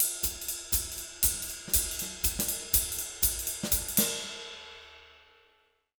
240SWING04-R.wav